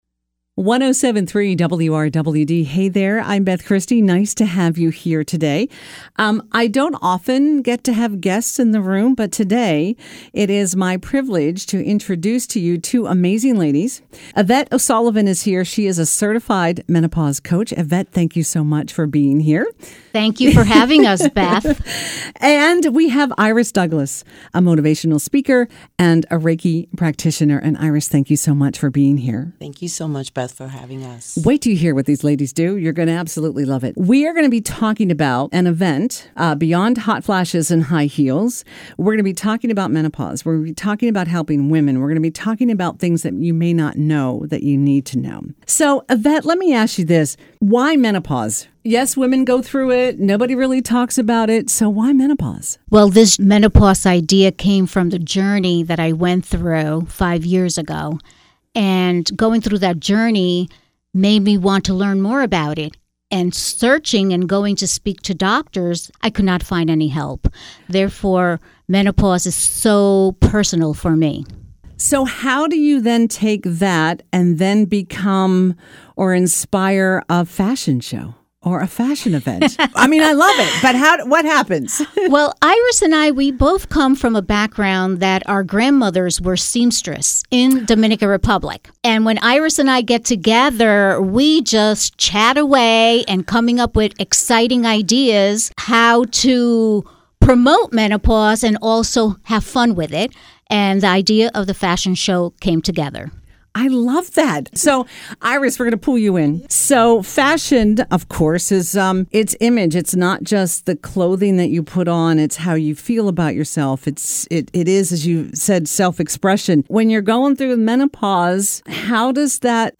• (00:00:00) - Two Women Talk Menopause